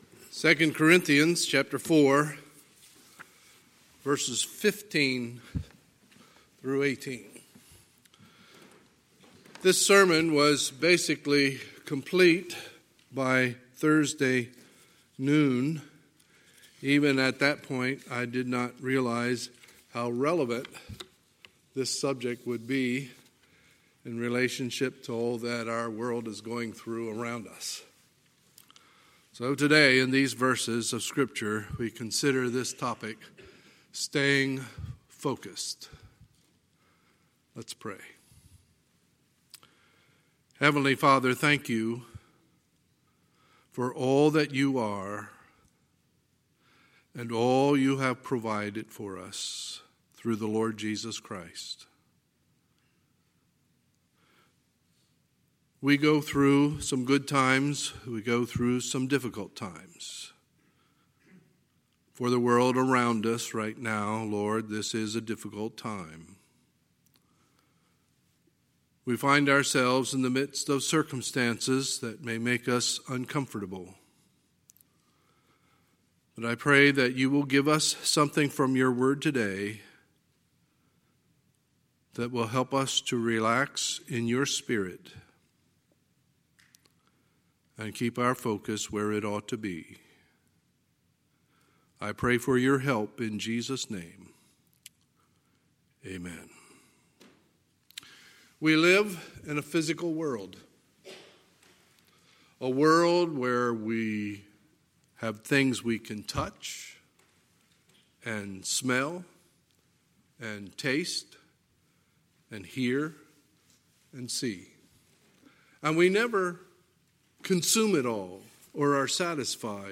Sunday, March 15, 2020 – Sunday Morning Service